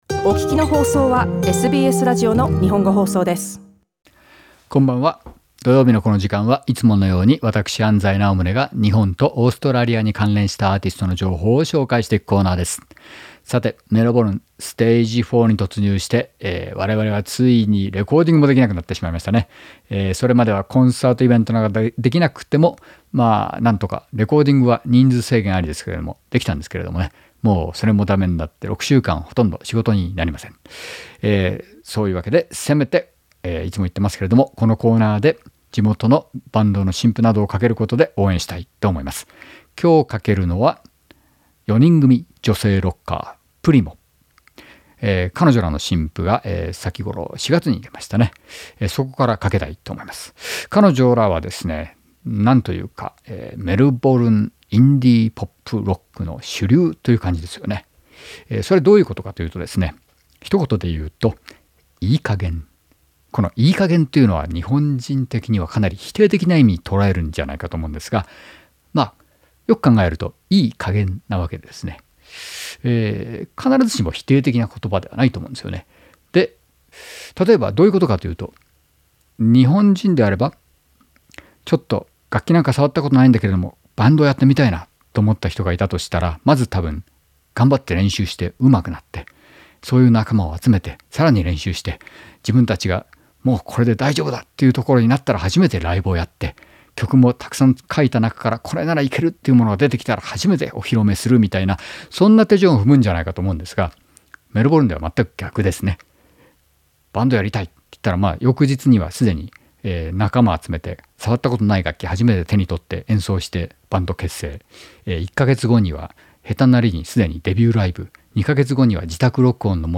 女性四人組
メルボルンのインディー・ポップロックの主流を行ってます。